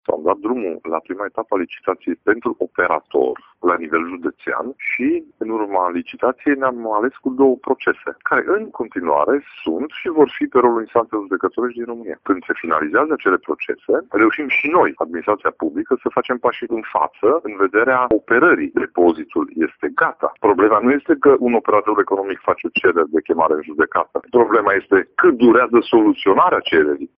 Acesta este funcțional, însă nu poate fi utilizat din cauza a două procese care au blocat, de mai bine de un an, licitația pentru desemnarea operatorului ce va gestiona acest depozit, spune președintele CJ Mureș, Ciprian Dobre: